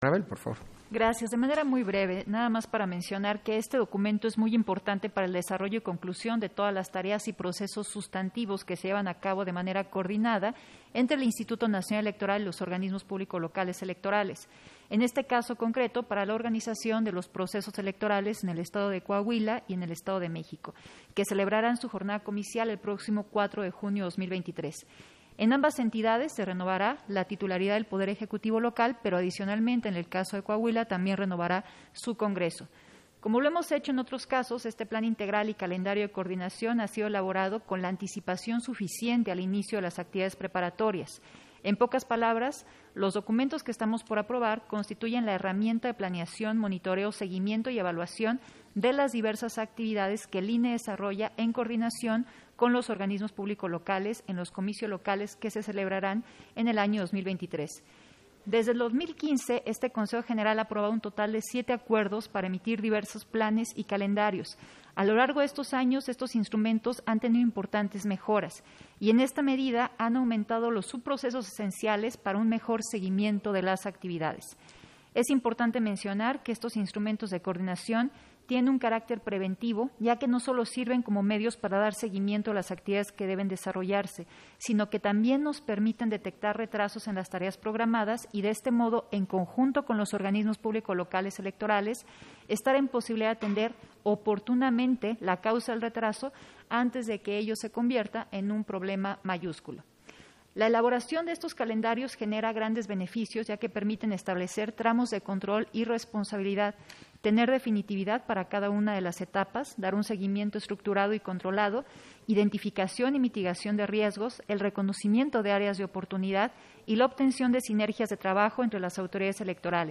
Intervención de Dania Ravel, en el punto 8 de la Sesión Extraordinaria, por el que se aprueba el Plan Integral y los calendarios de coordinación de los procesos electorales 2023